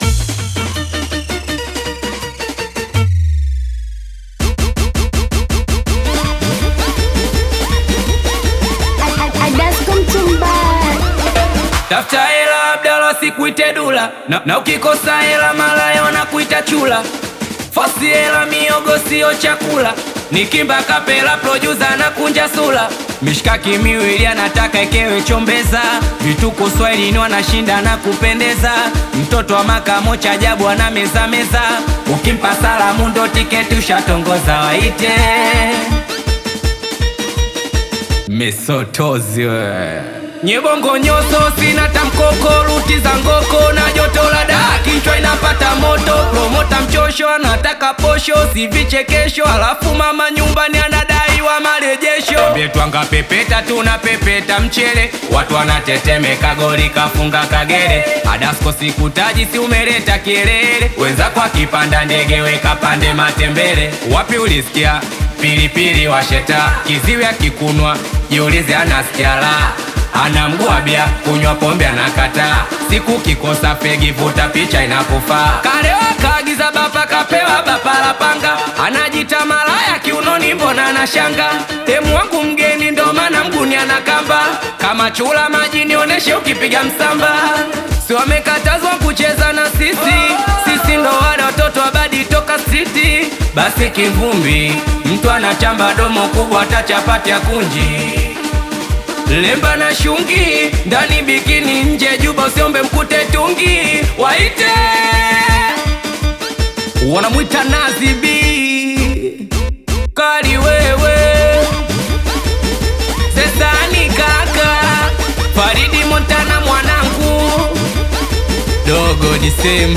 high-energy Tanzanian Singeli/Bongo Flava single